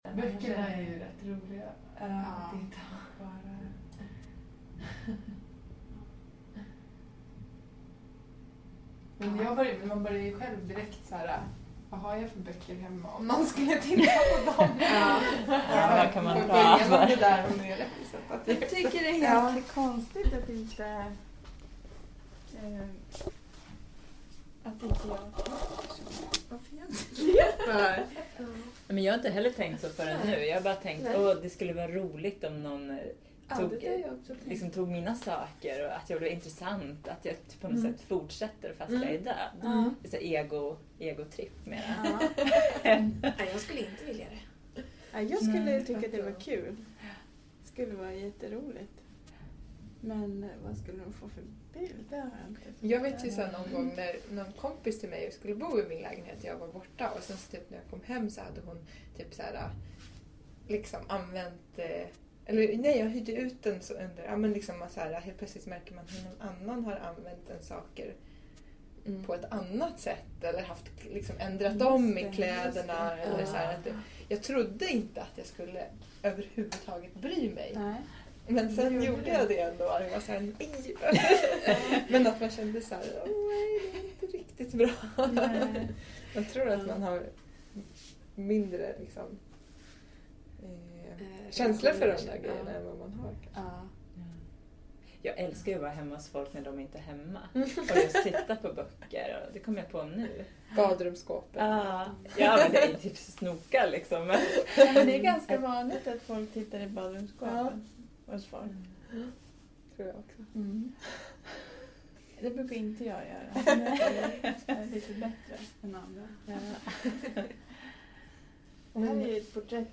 samtal